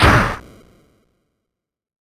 signExplode.ogg